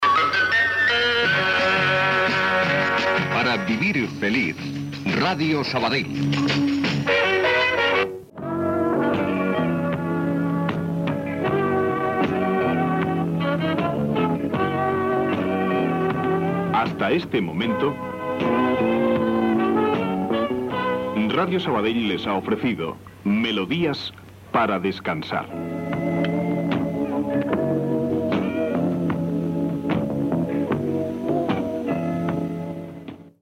Indicatiu de l'emissora i final del programa
Musical